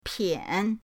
pian3.mp3